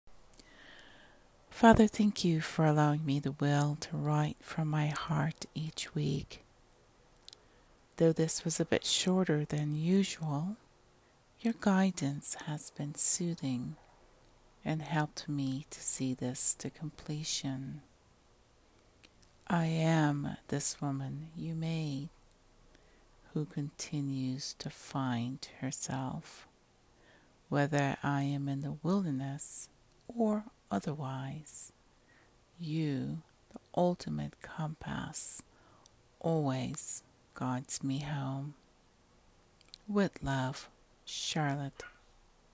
Spoken words: